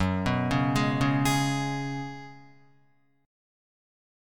F#sus2sus4 chord